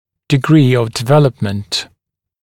[dɪ’griː əv dɪ’veləpmənt][ди’гри: ов ди’вэлэпмэнт]степень развития, степень развитости